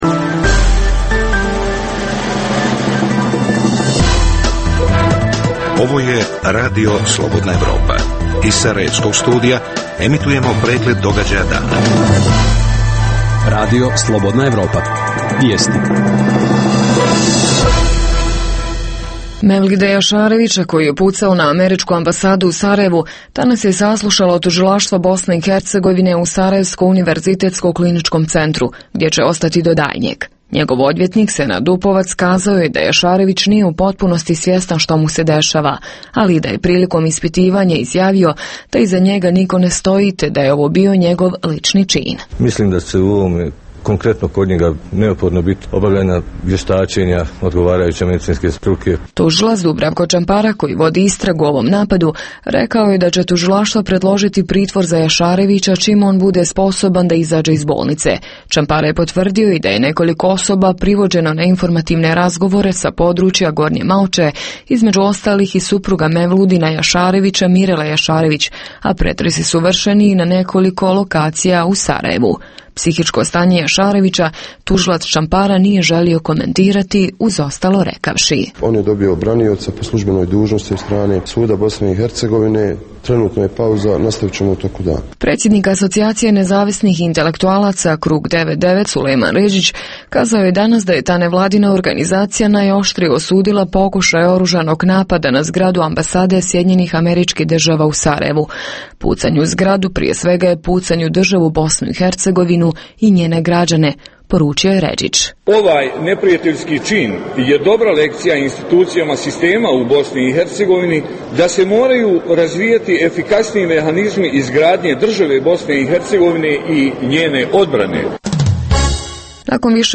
vijesti, analize, reportaže i druge rubrike o putu BiH ka Evropskoj uniji. U emisiji objavljujemo: - Da li je EU, zaokupljena vlastitim problemima, zaboravila na jedan od proklamovanih ciljeva – proširenje?